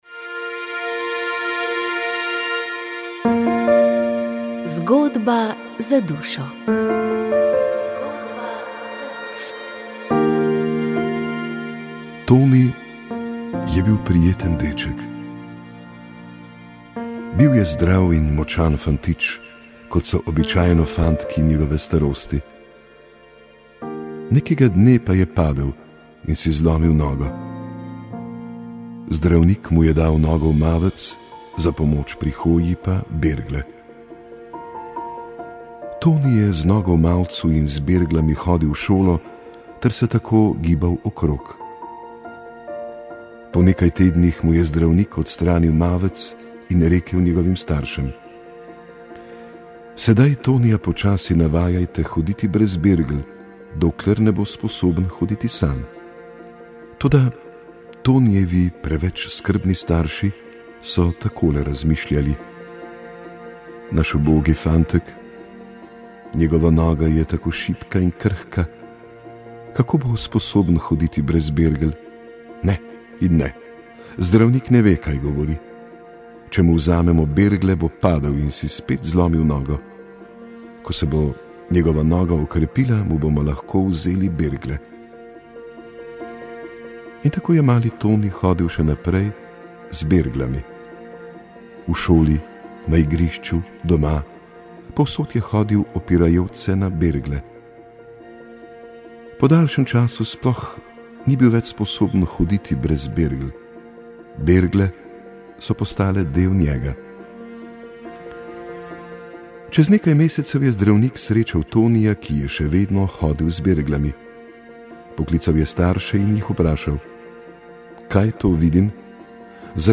V oddaji Utrip Cerkve boste slišali o petem mednarodnem srečanju škofov in vernikov iz Avstrije in Slovenije. Slišali boste tudi pogovor